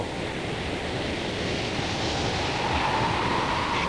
WIND1.mp3